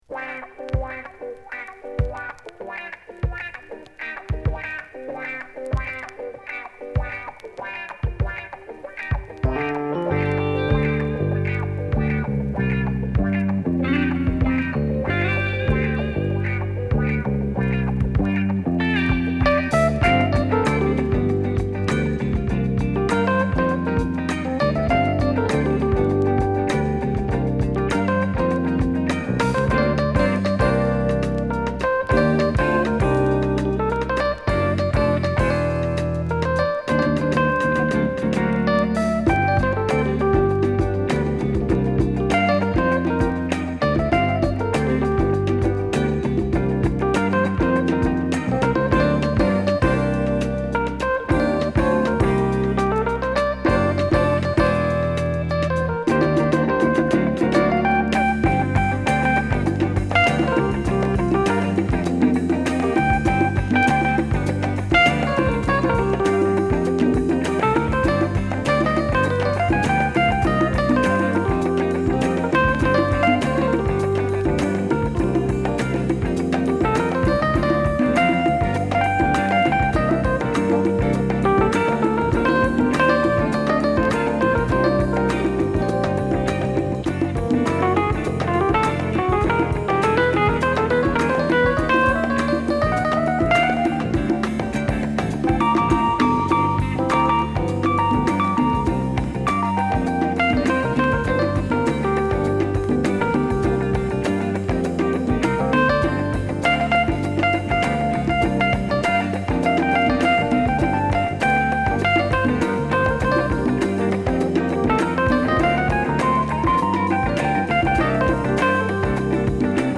メロウからファンキーなものまで、文句無しの名曲揃い！！